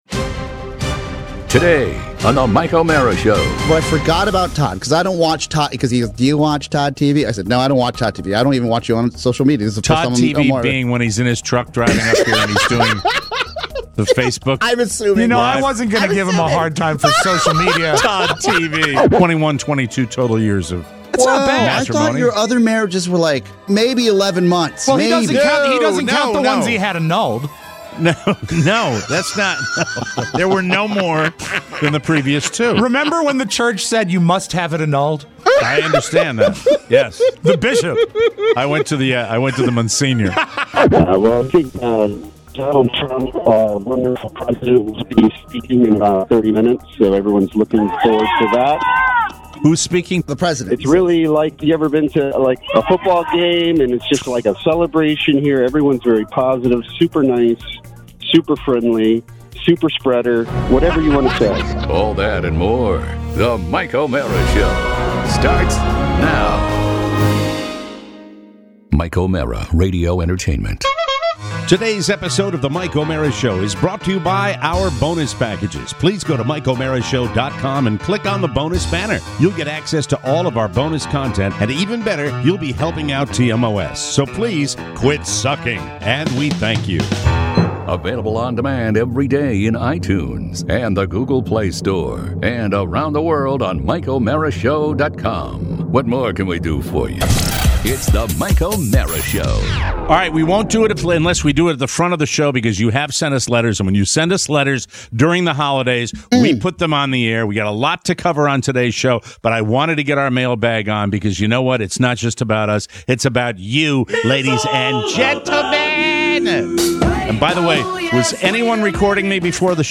checks in live from the rally in DC! Plus: a proud talkup... forgotten mittens, and your letters.